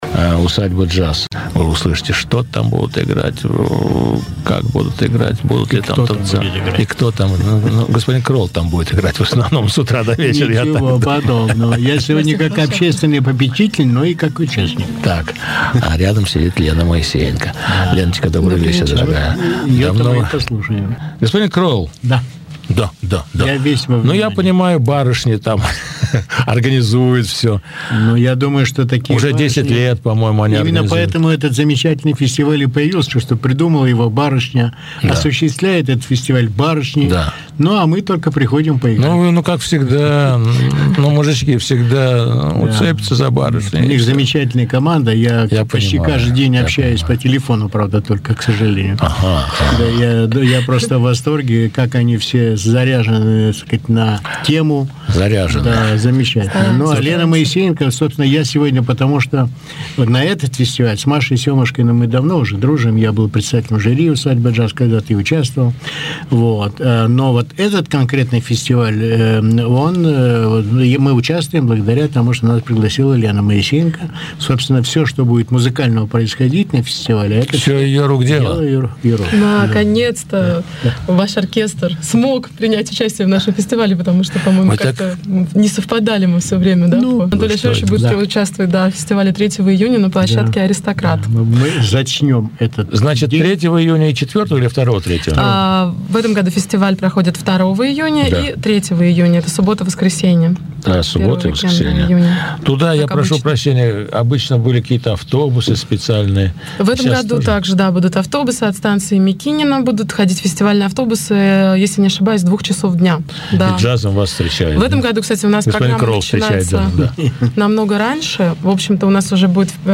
9-й фестиваль "УСАДЬБА-ДЖАЗ". 2-3 июня "АРХАНГЕЛЬСКОЕ". С организаторами беседует